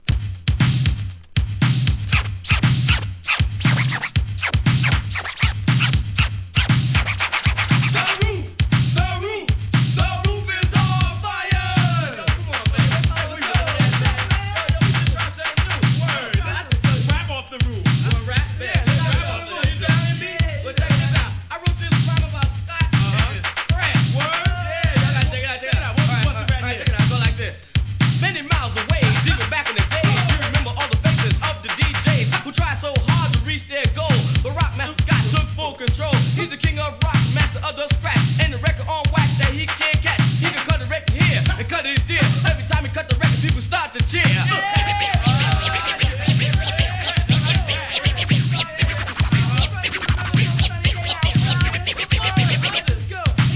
HIP HOP/R&B
エレクトロOLD SCHOOL名盤!!